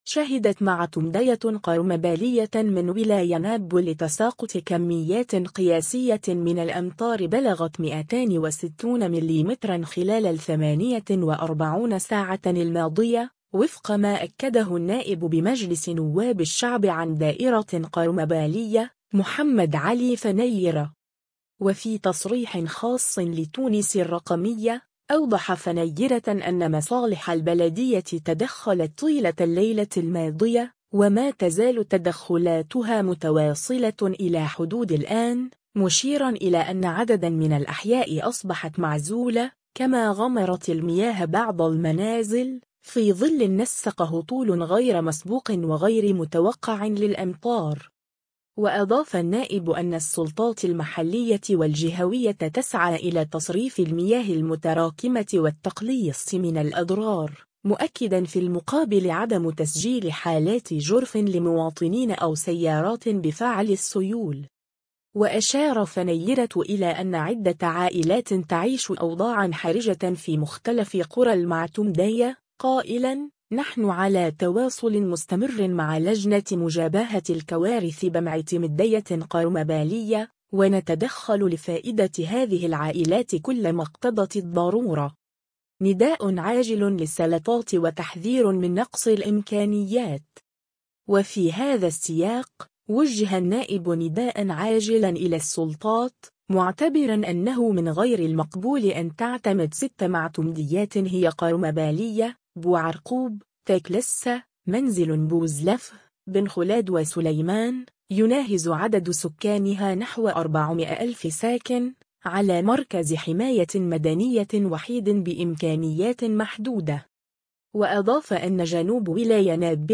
وفي تصريح خاص لـ”تونس الرقمية”، أوضح فنيرة أن مصالح البلدية تدخلت طيلة الليلة الماضية، وما تزال تدخلاتها متواصلة إلى حدود الآن، مشيرًا إلى أن عددا من الأحياء أصبحت معزولة، كما غمرت المياه بعض المنازل، في ظل نسق هطول غير مسبوق وغير متوقع للأمطار.